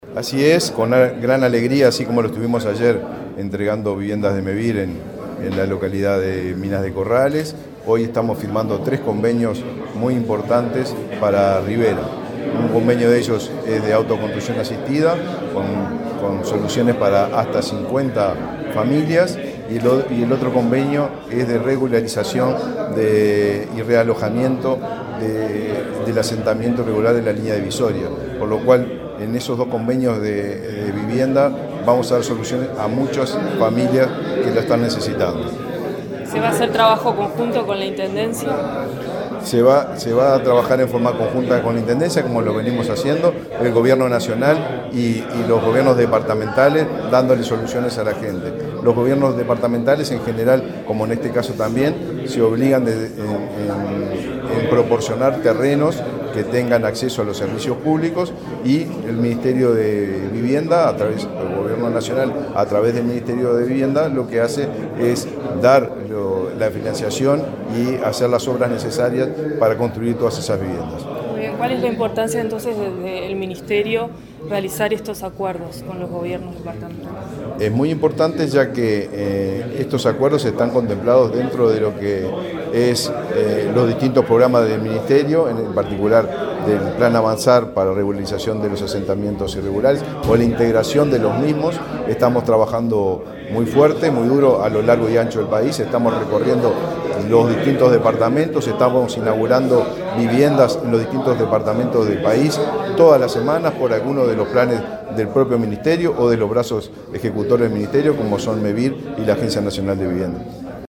Entrevista al ministro de Vivienda, Raúl Lozano
El titular de la cartera, Raúl Lozano, dialogó con Comunicación Presidencial acerca del alcance del acuerdo.